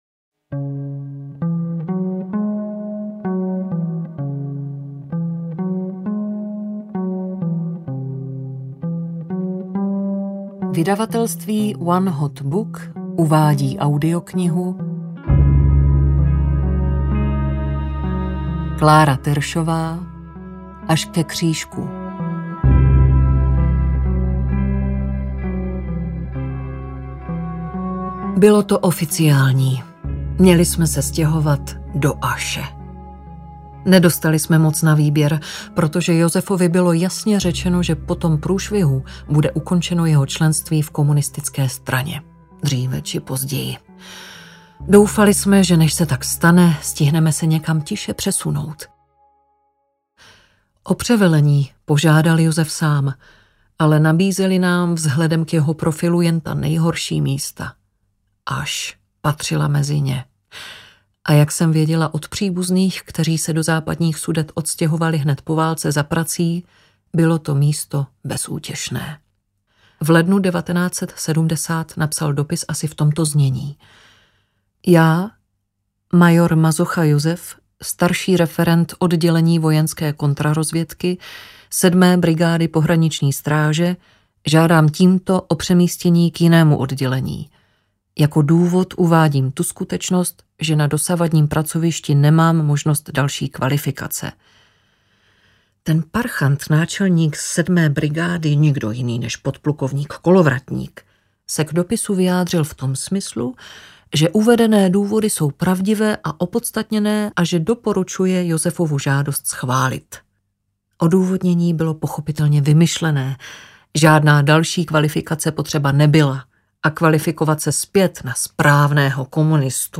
Až ke křížku audiokniha
Ukázka z knihy